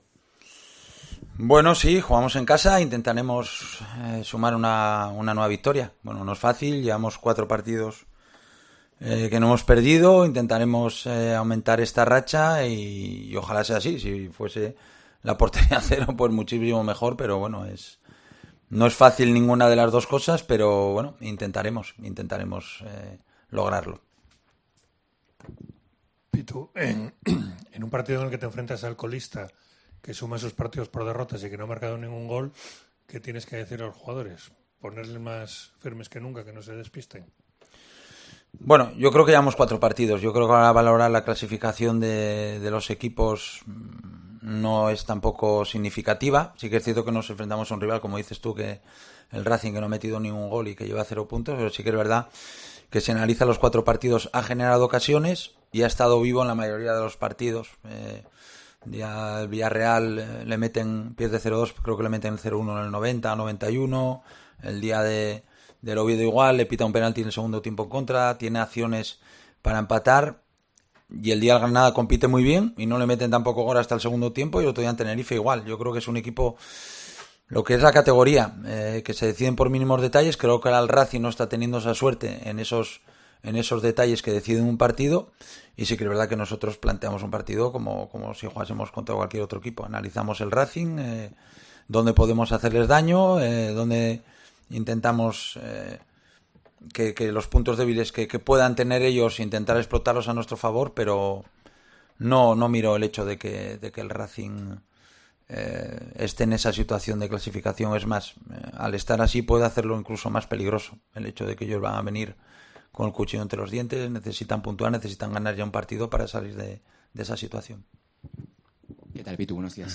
El Pitu Abelardo compareció en rueda de prensa para abordar el choque ante el Racing de Santander en El Molinón.